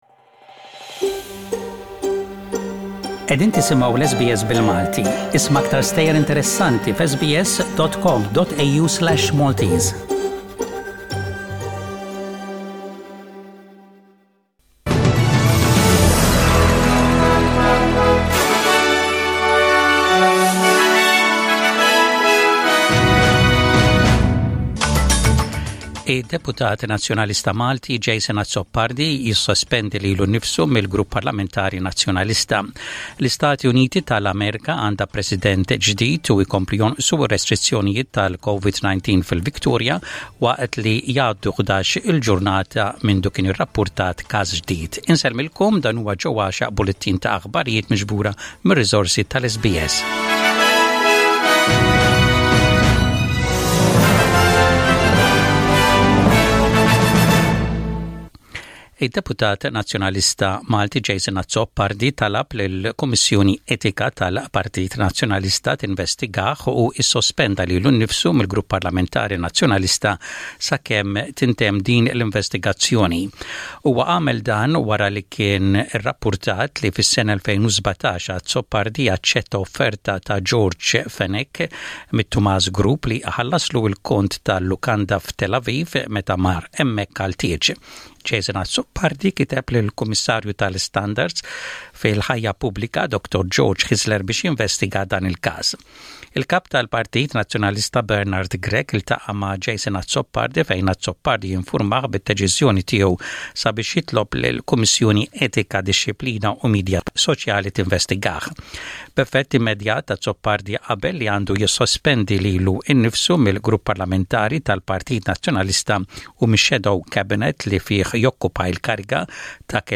SBS Radio | Aħbarijiet bil-Malti: 10/11/20